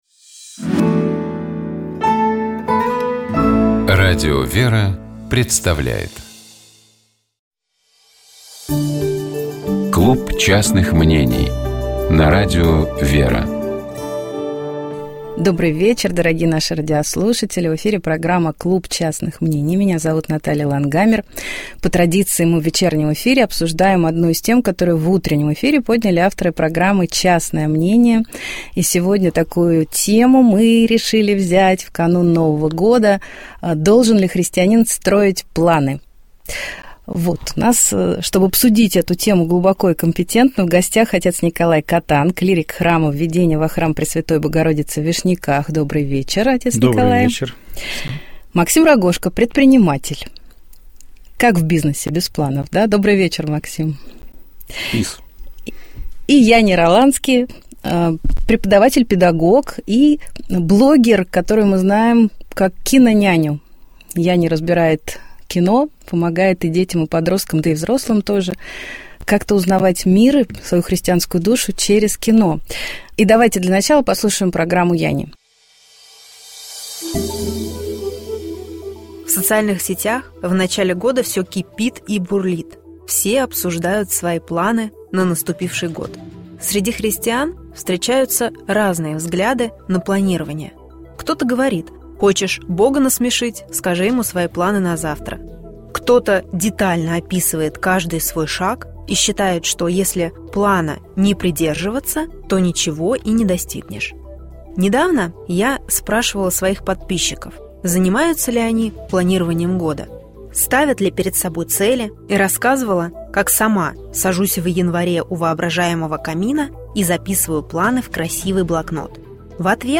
Мы говорили с нашим гостем о его пути к вере, об интересных людях, повлиявших на этот путь, а также о том, как еще в советские годы он начинал организовывать экскурсии, связанные с религиозными темами, как создавались различные паломнические маршруты и как они развиваются и меняются сегодня.